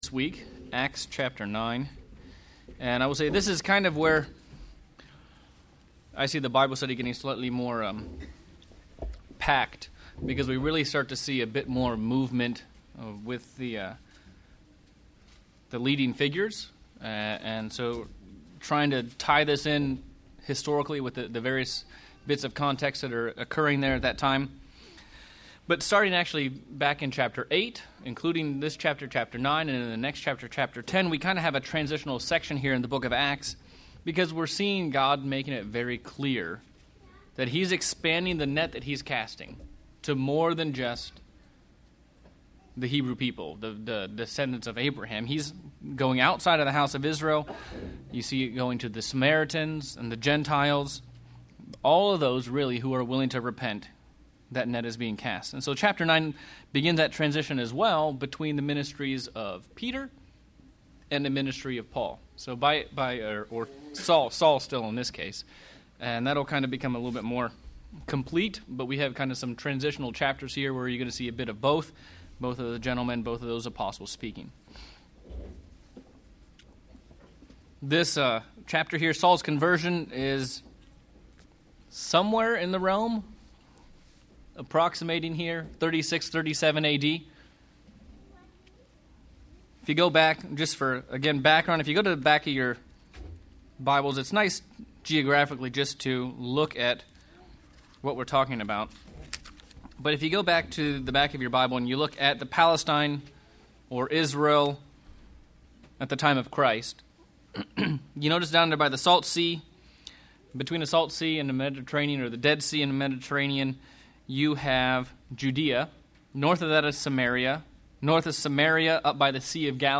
Bible Study: Acts of the Apostles - Chapter 9:1-31